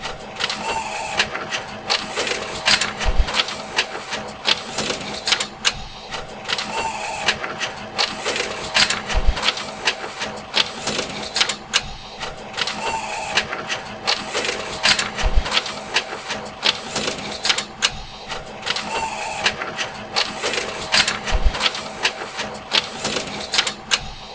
CopyMachine_2.wav